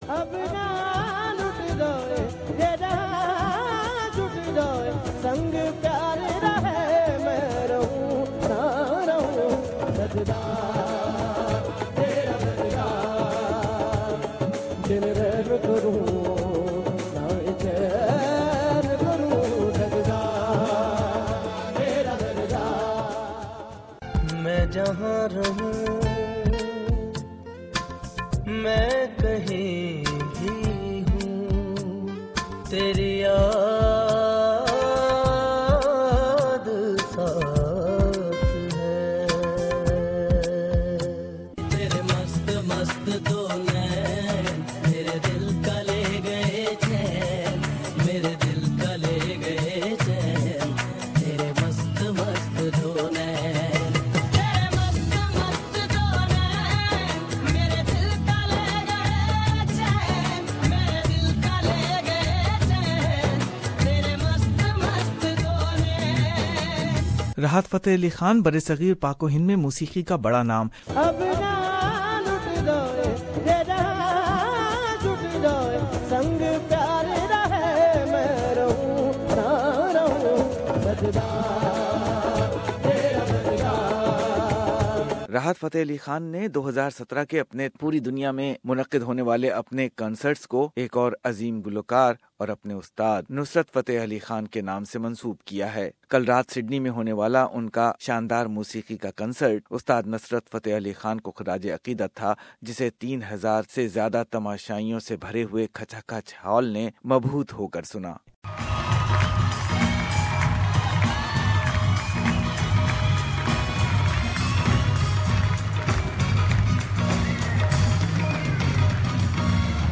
Rahat Fatah Ali Khan perform his Tribute Tour 2017 in Sydney, in honor of his uncle Ustad Nusrat Fateh Ali Khan. Listen the musical grabs and interview based feature of living legend of our era.